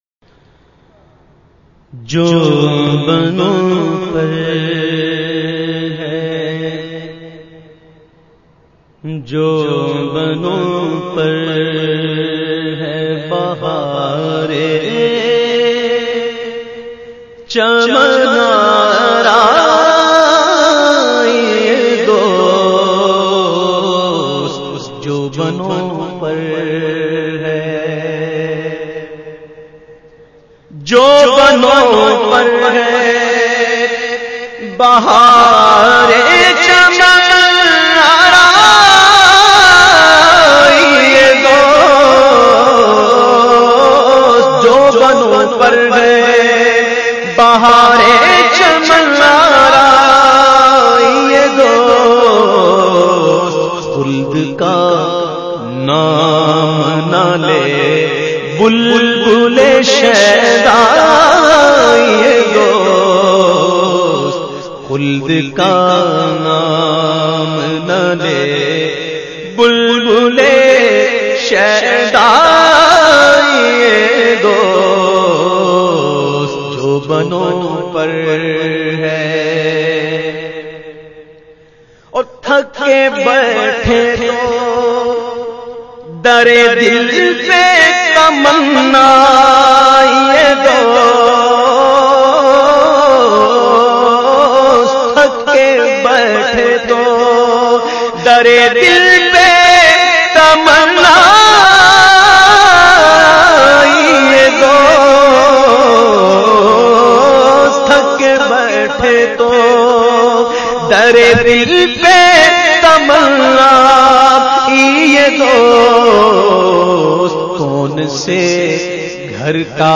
The Naat Sharif Jo Banoo Par Hay recited by famous Naat Khawan of Pakistan Owais Raza Qadri.